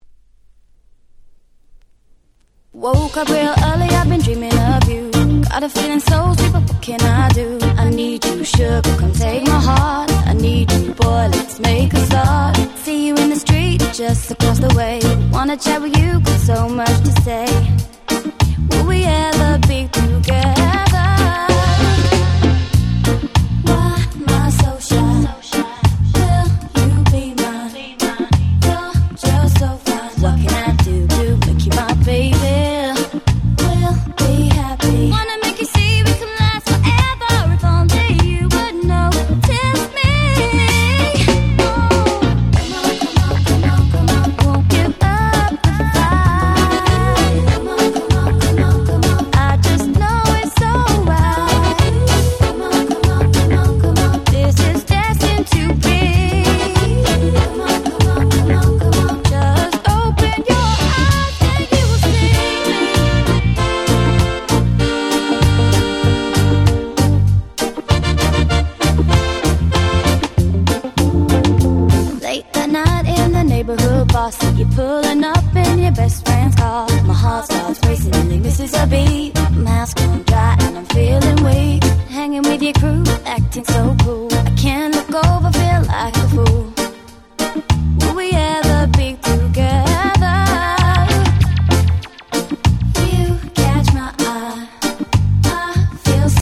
08' Nice Regga R&B !!